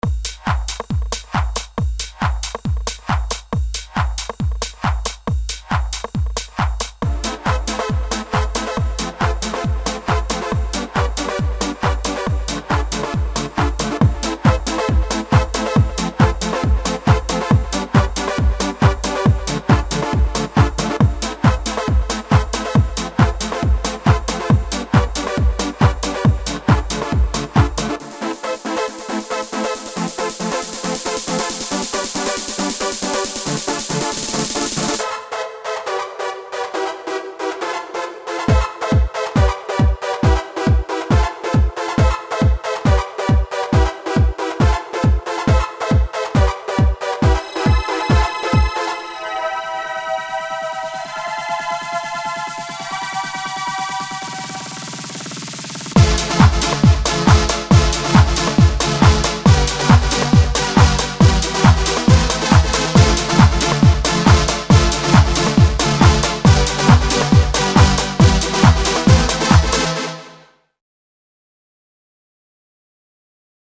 dance/electronic
Trance
Club Anthems